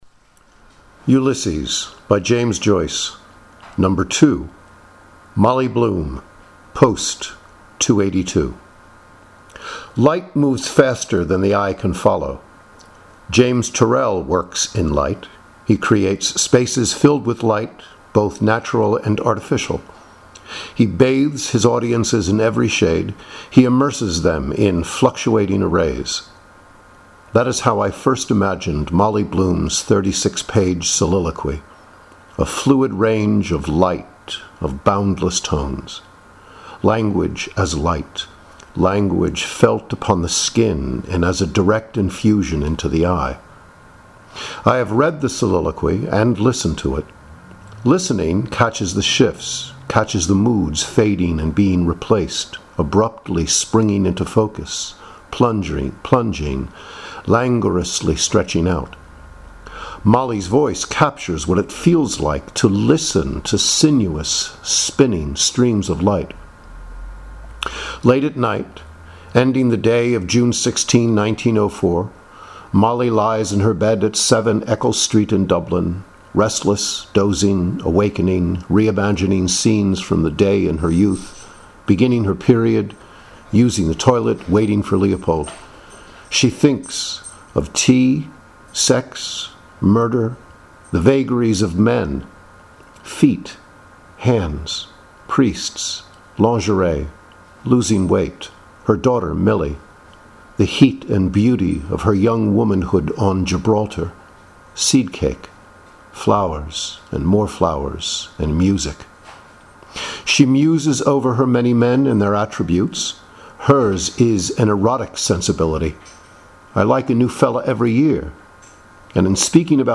#I like listening to how the actress performs this; her pauses provide clarity and are necessary for that purpose.